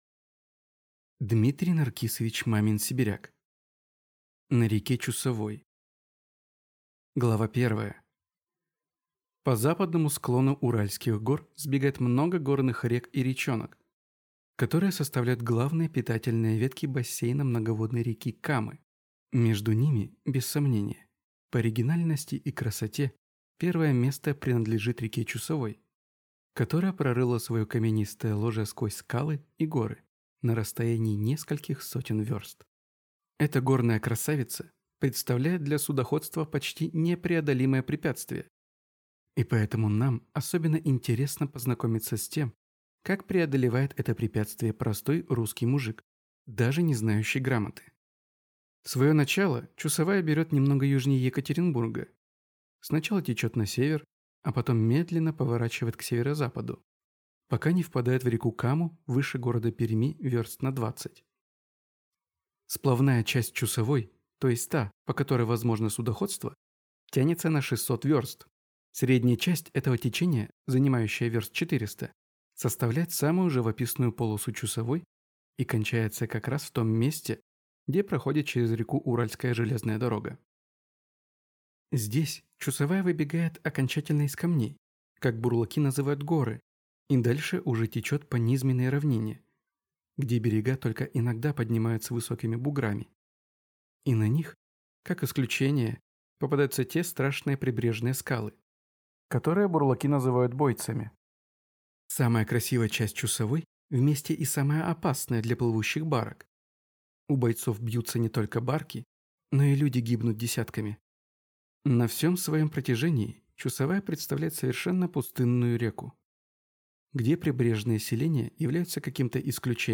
Аудиокнига На реке Чусовой | Библиотека аудиокниг